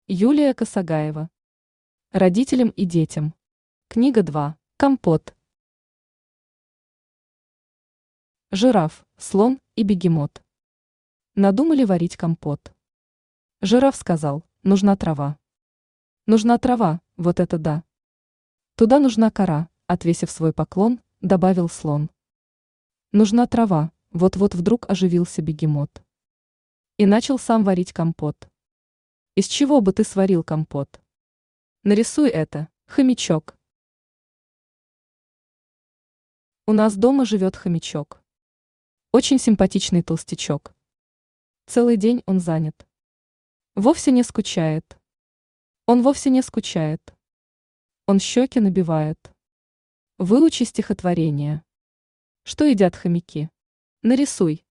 Аудиокнига Родителям и детям. Книга 2 | Библиотека аудиокниг
Книга 2 Автор Юлия Косагаева Читает аудиокнигу Авточтец ЛитРес.